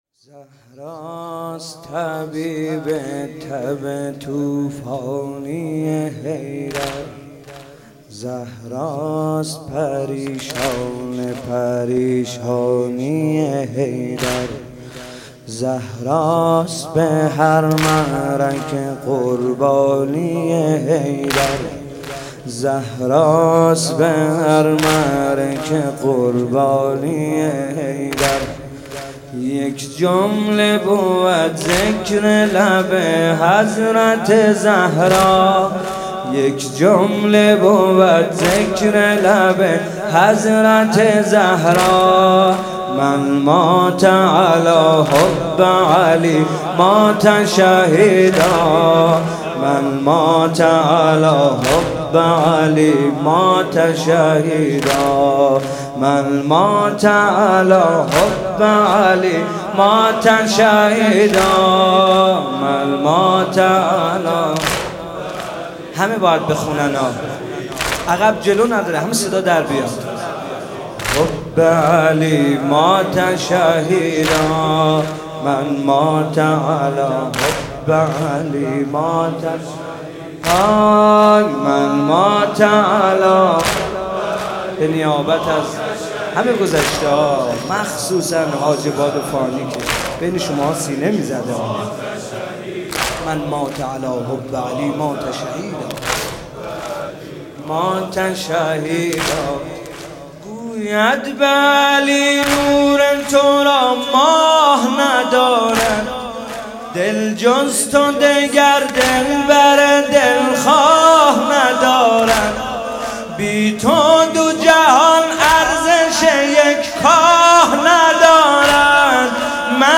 فاطمیه دوم 95 - شب 1 - واحد - زهراست طبیب تب